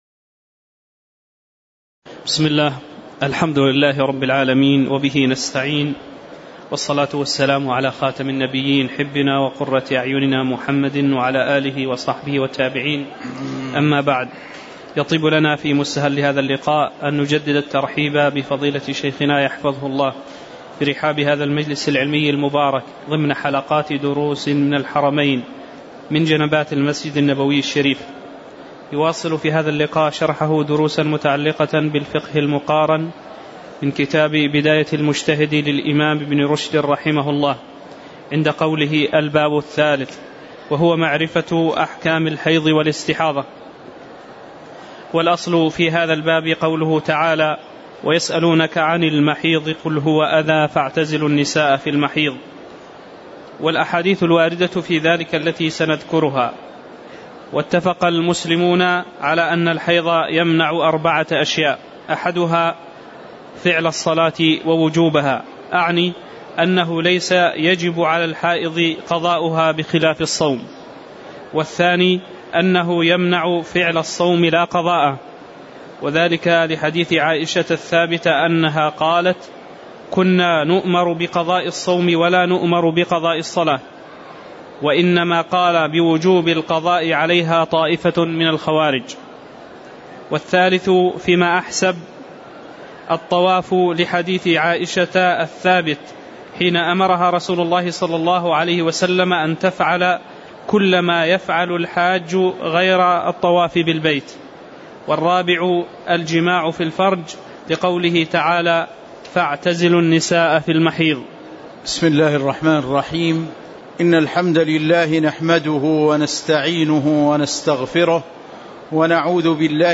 تاريخ النشر ٢٨ جمادى الأولى ١٤٤٠ هـ المكان: المسجد النبوي الشيخ